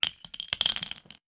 weapon_ammo_drop_16.wav